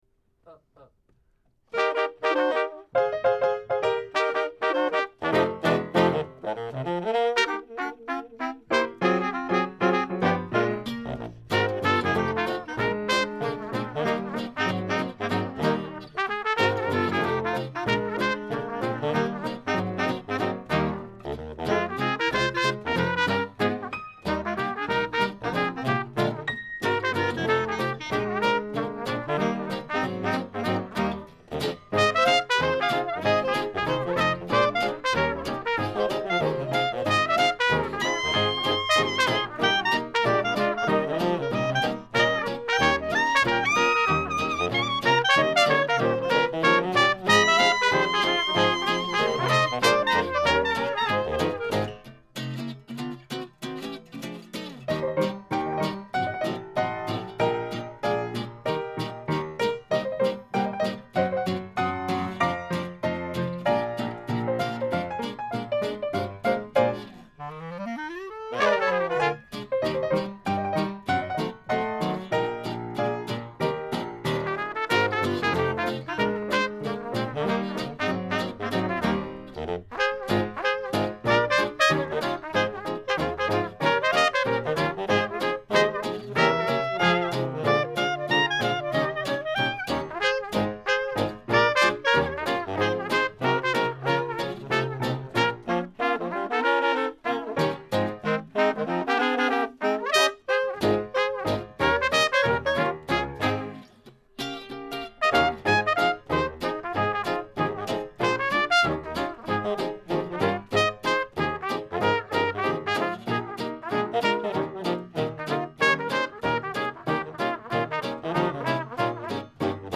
saxophone basse, trombone, chant
trompette
clarinette, saxophone ténor
piano
guitare, banjo